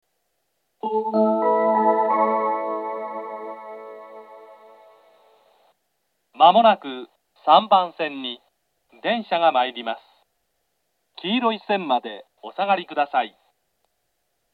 接近放送があり、「東海道型」の放送が使用されています。
３番線接近放送 男声の放送です。